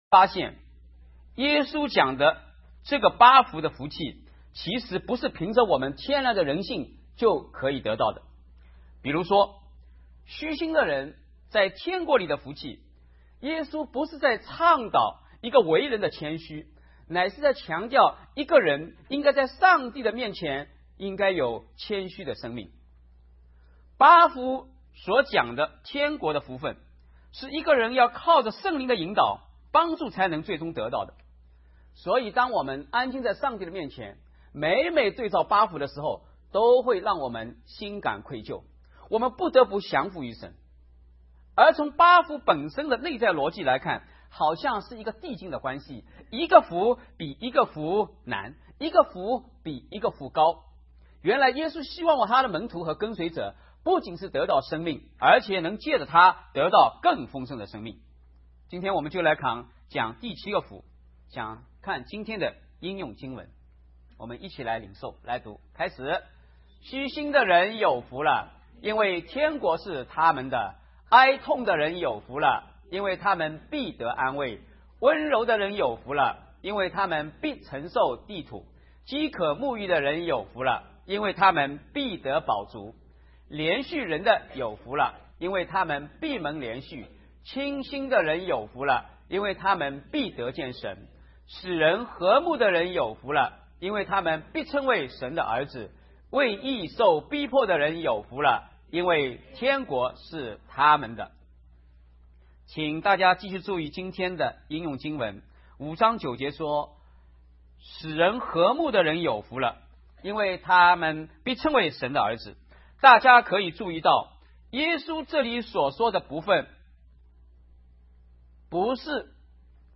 “使人和睦的人有福了” 講員